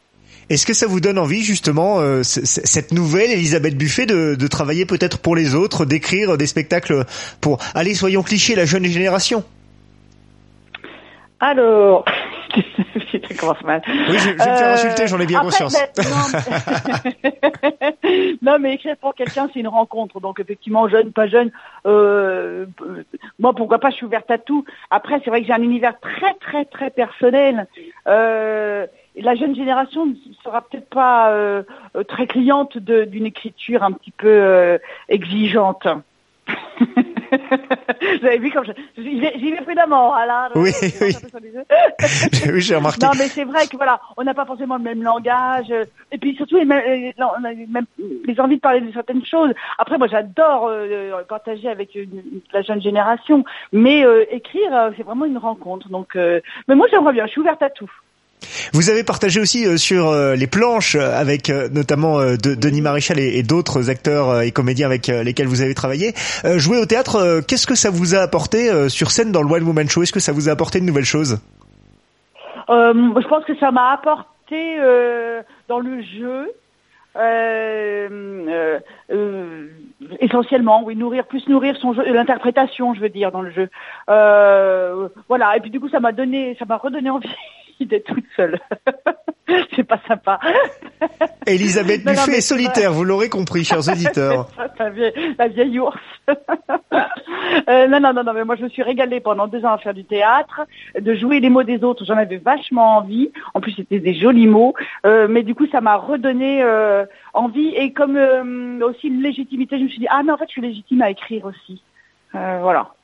Une interview tout en confidence et en humour avec le charme d’Elisabeth Buffet qui cohabite avec son franc-parler, pour des moments très drôles dans cet entretien!